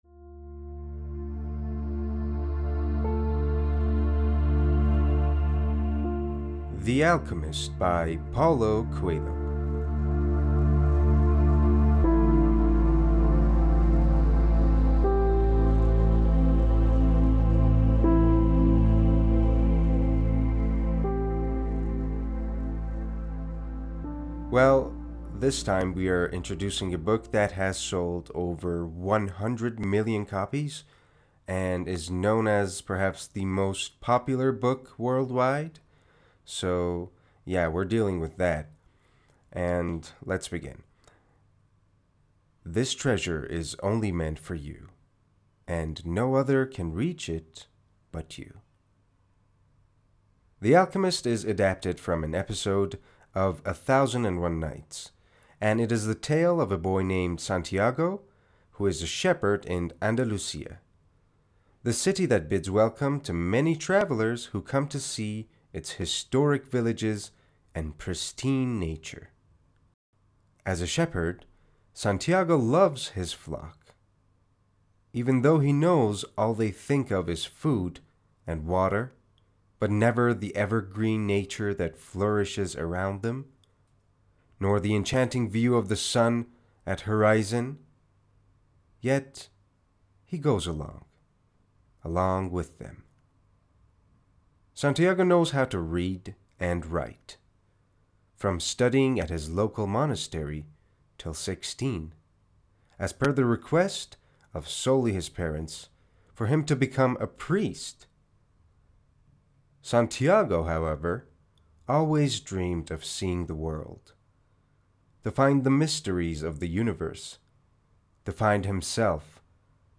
معرفی صوتی کتاب The Alchemist